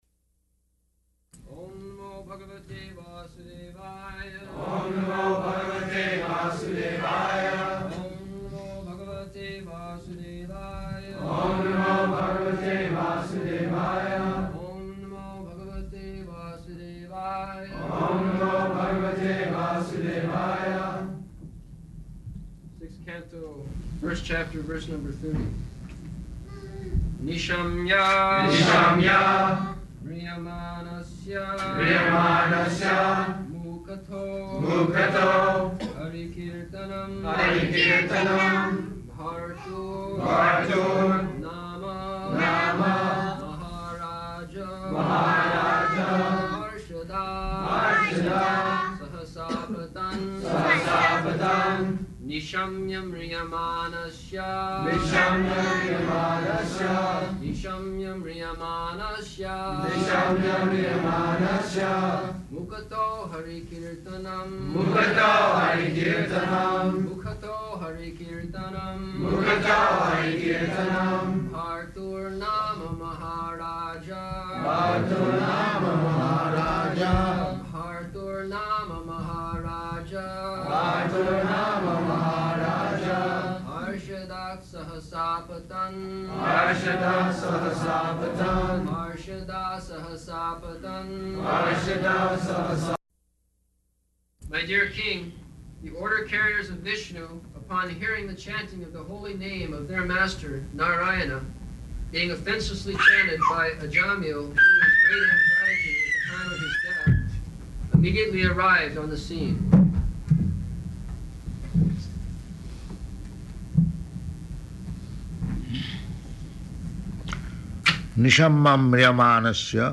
July 14th 1975 Location: Philadelphia Audio file
[devotees repeat] Sixth Canto, First Chapter, verse number 30.